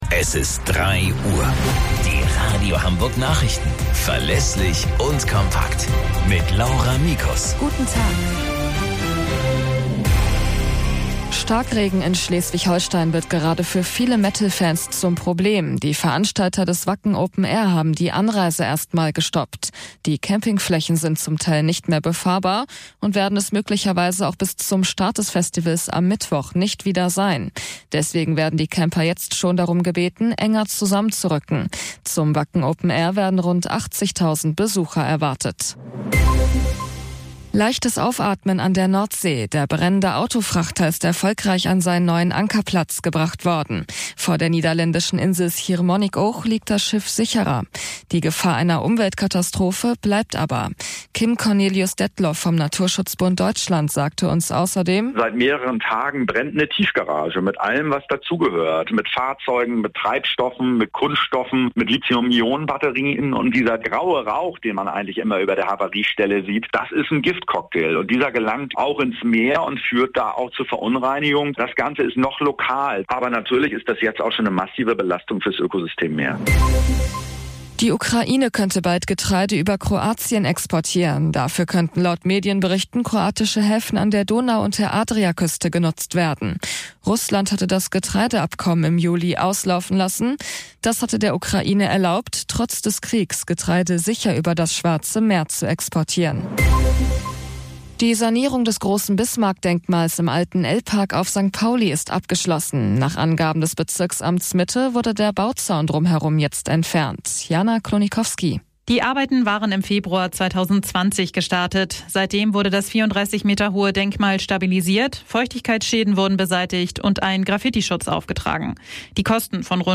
Radio Hamburg Nachrichten vom 01.08.2023 um 09 Uhr - 01.08.2023